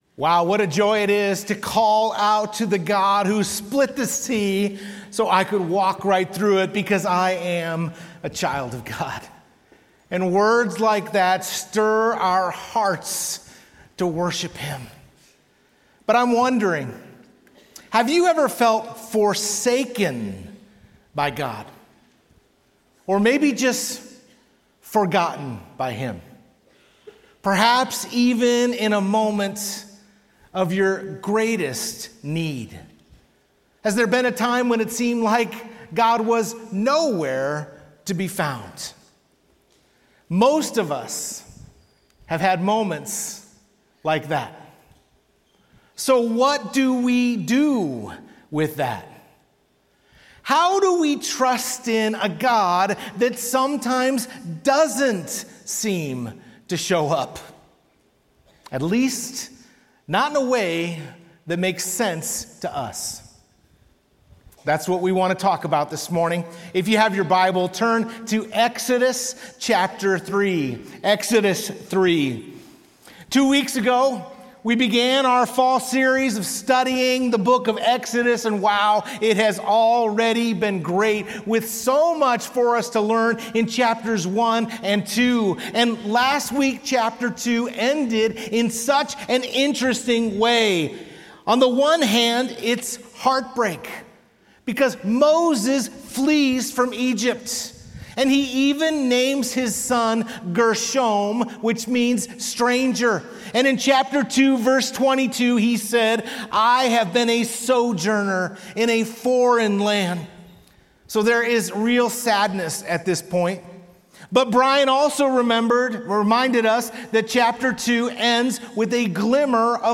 Sermon: The Plan of I AM